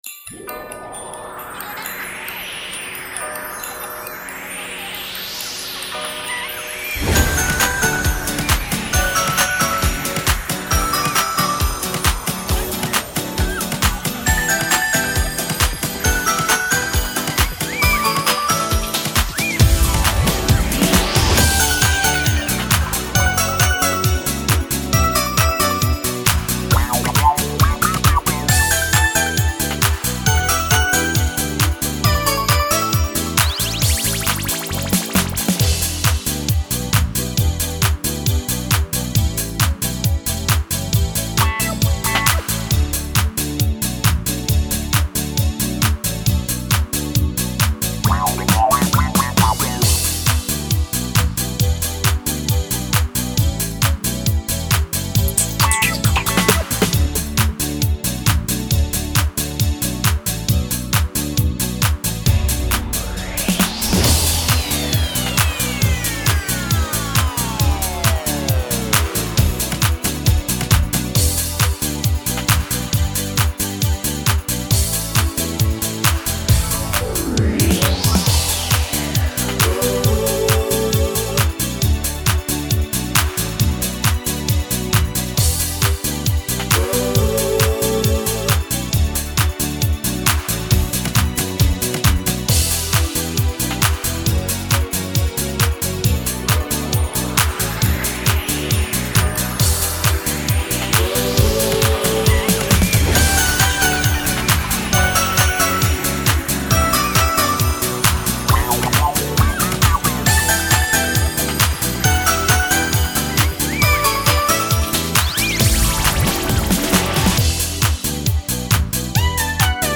• Категория: Детские песни
| караоке
🎵 минусовка